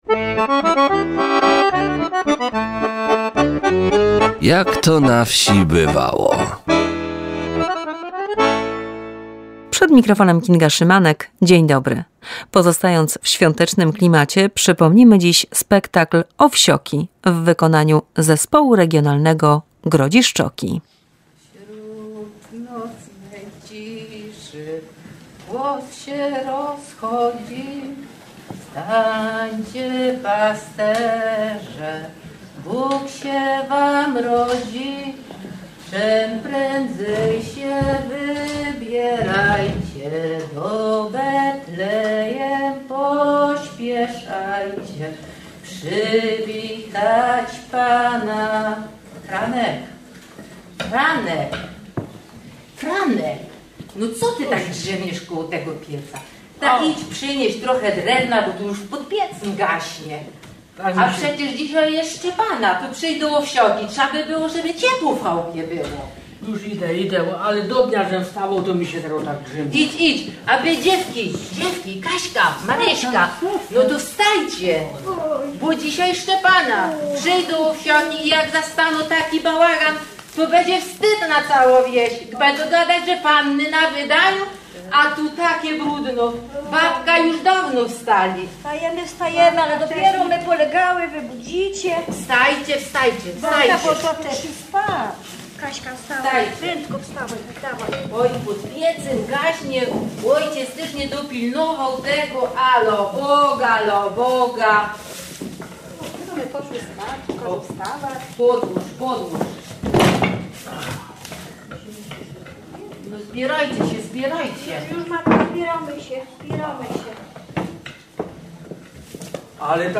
Widowisko "Owsioki" w wykonaniu Zespołu Grodziszczoki.